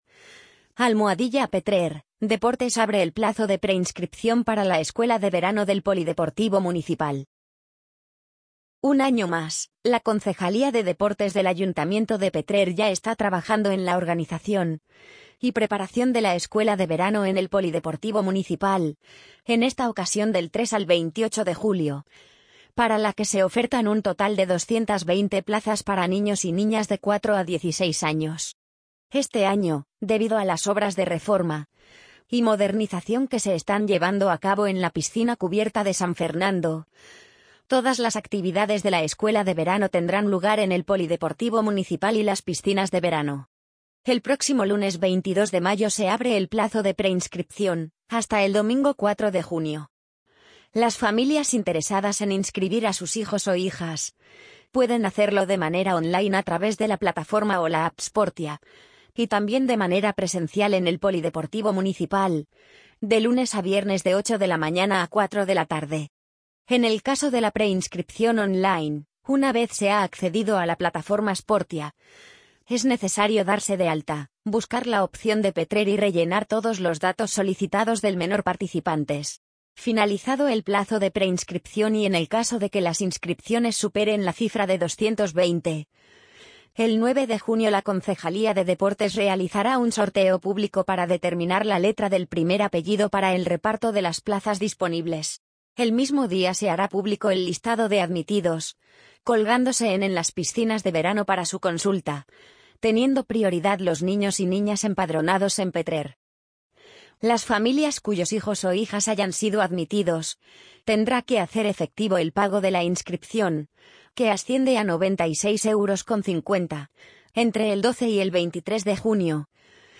amazon_polly_66148.mp3